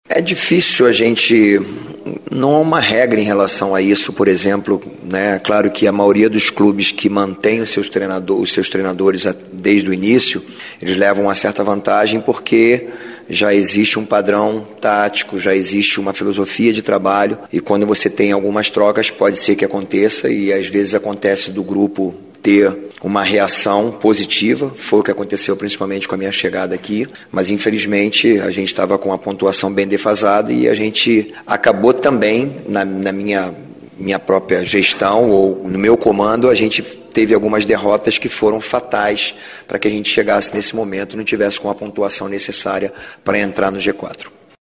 Na entrevista coletiva que concedeu após esta partida, o técnico Jorginho disse que faltou ao Coritiba frieza e experiência para segurar o placar em algumas partidas da Série B, como na derrota para o Paysandu, quando o time paraense teve um jogador expulso e, mesmo assim, virou o placar para 2 a 1, em Belém.
Ouça a declaração do treinador: